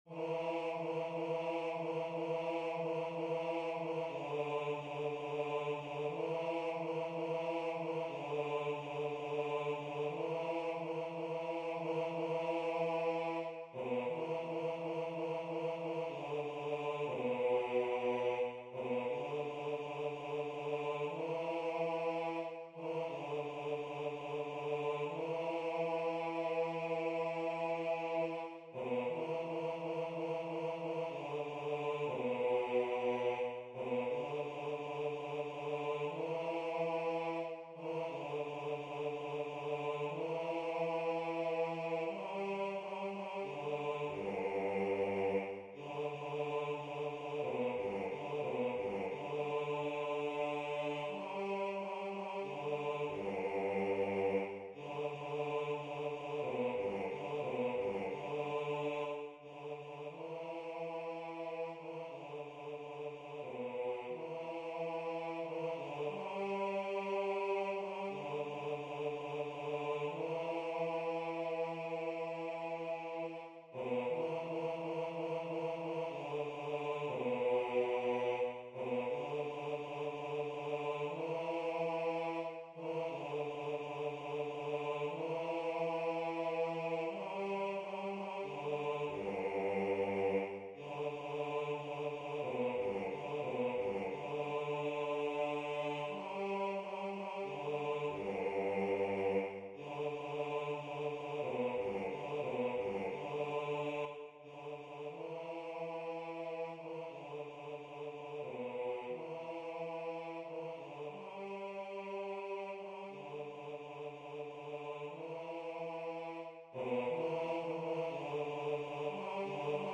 TXORIA-TXORI-ERREPIKA-Basse.mp3